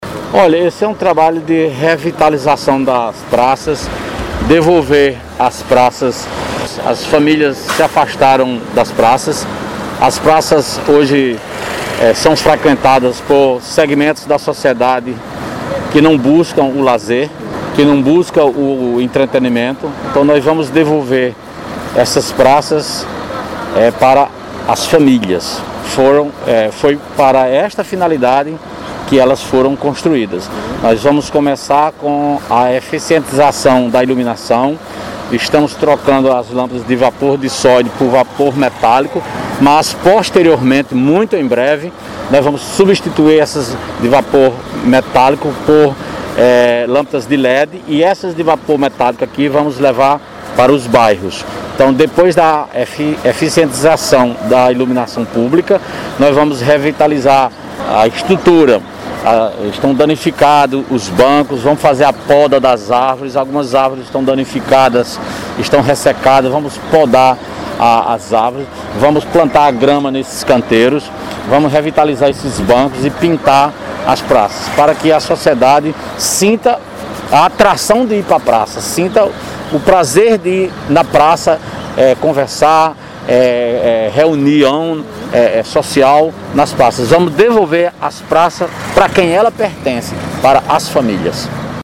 Fala do prefeito Ivanes Lacerda sobre a reposição de lâmpadas nas praças e outros serviços em toda a cidade: